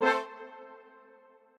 strings8_37.ogg